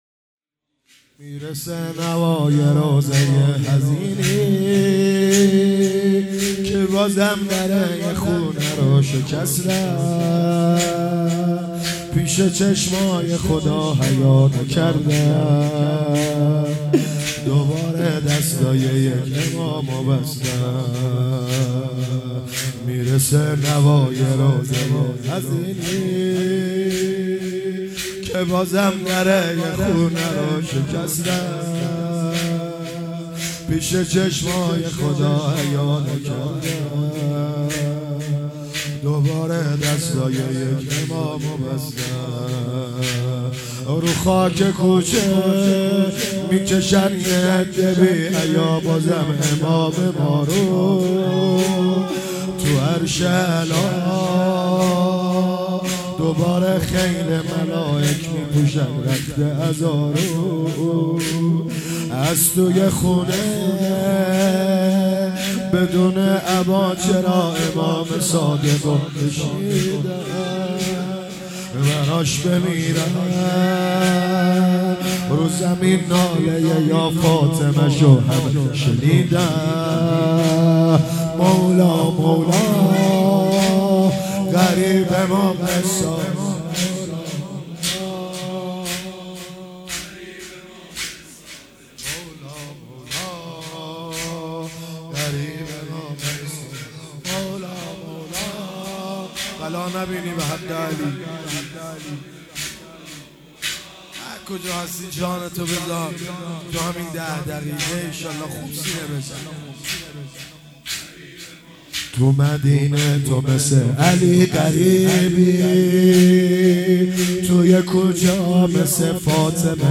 شهادت امام صادق ۹۷/هیئت مکتب المهدی (عج)